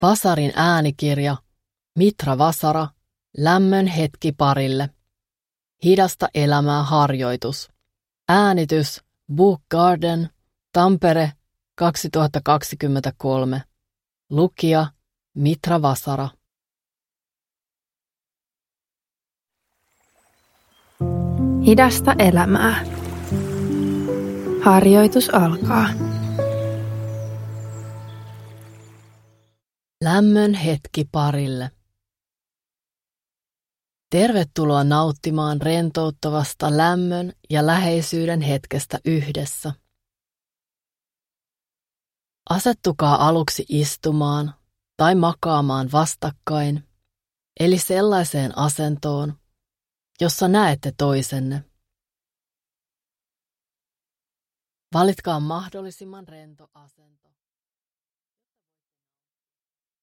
Lämmön hetki parille – Ljudbok – Laddas ner
Parihetki-harjoitus